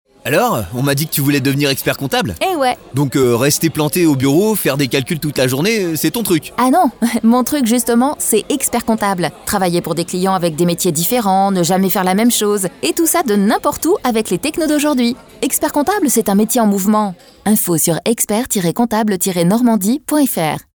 Spot 1